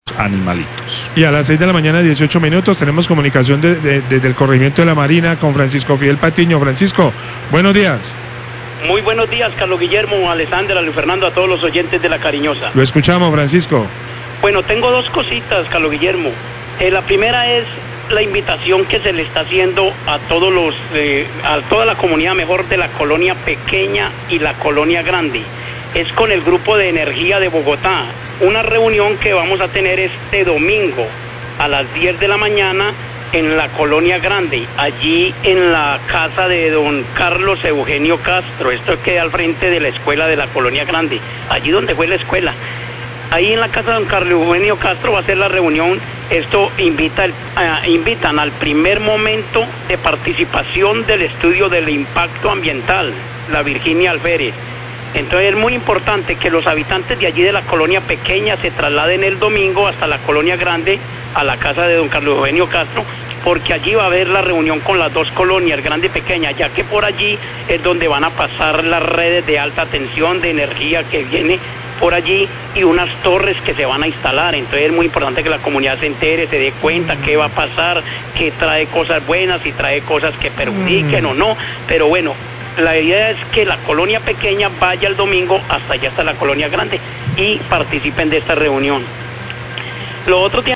Oyente de La Marina invita a a reunión con GEB sobre impacto ambiental por instalación de redes de energía, La Cariñosa 617am
Radio